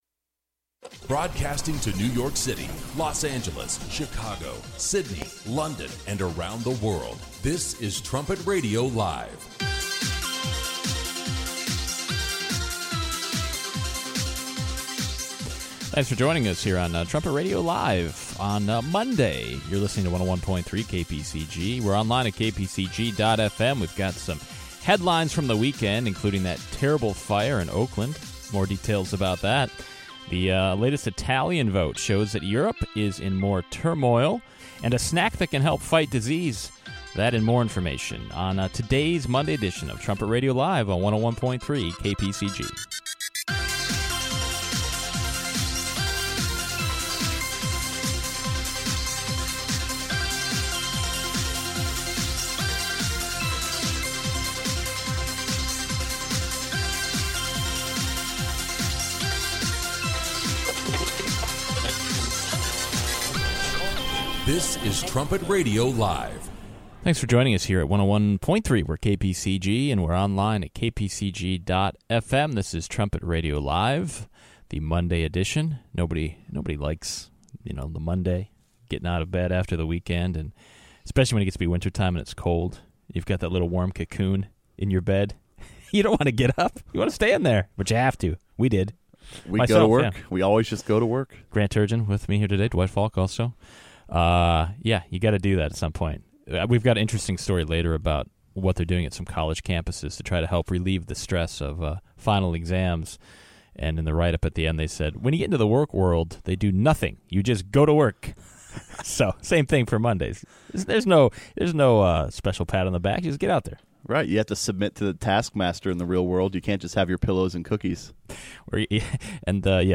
Trumpet Radio Live seeks to provide God’s view of the current headlines in an informative, stimulating, conversational and occasionally humorous way. Tune in each day at 10 a.m. CST to listen in live.